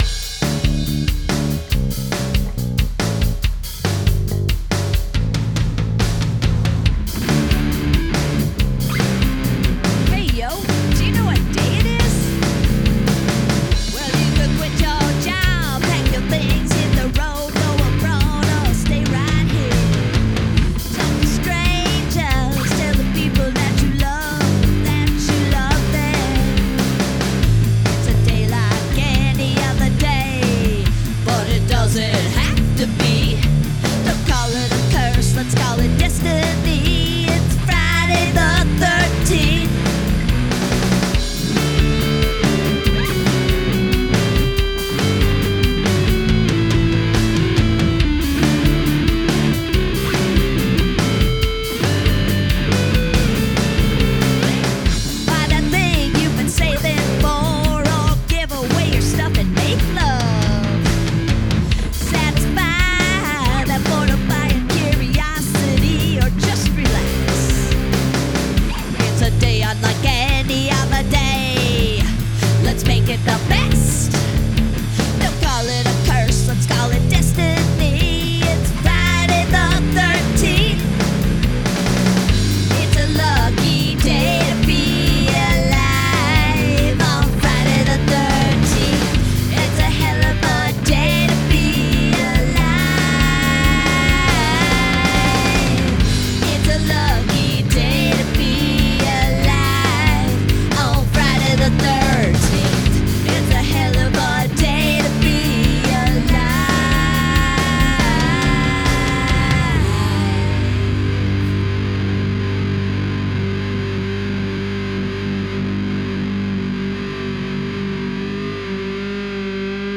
write a feel-good song with happy lyrics and upbeat music
:!: Punk whine, not happy punk.